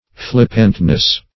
Flippantness \Flip"pant*ness\, n.